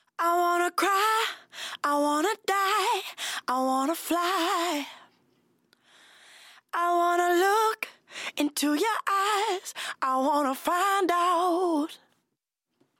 Solo Voz Sin Comprimir